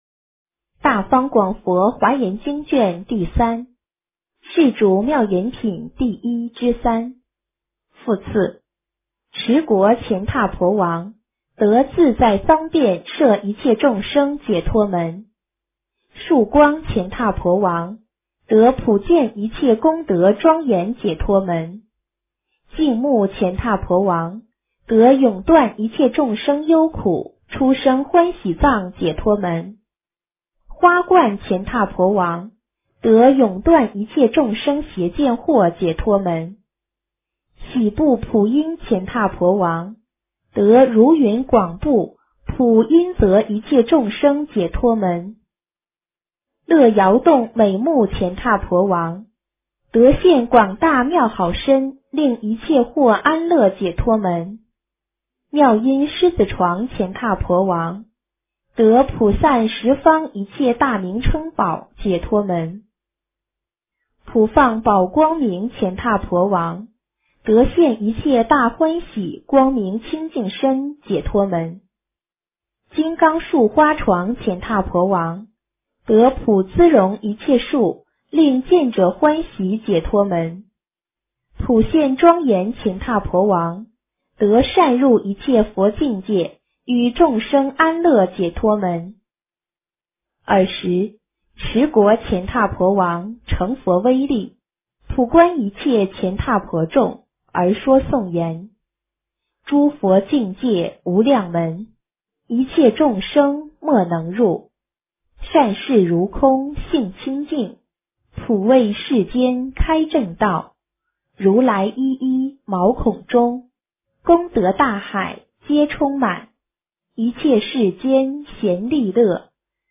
华严经03 - 诵经 - 云佛论坛